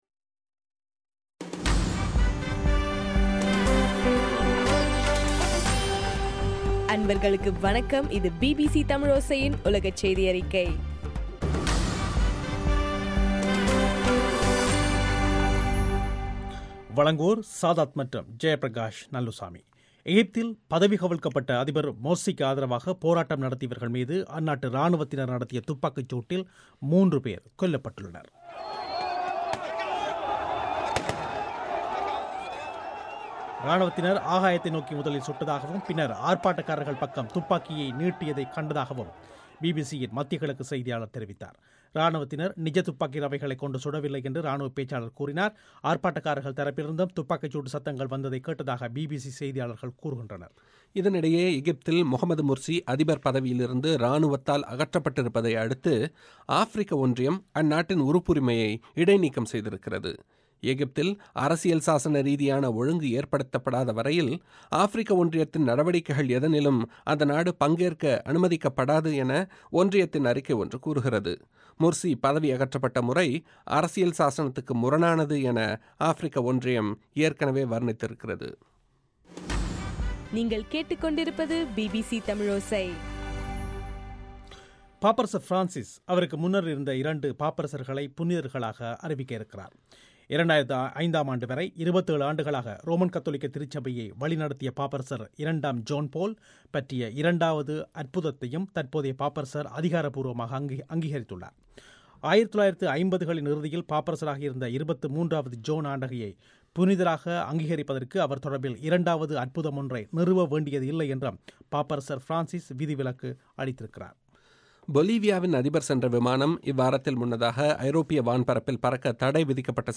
பிபிசி தமிழின் சர்வதேச செய்தியறிக்கை 05/07/2013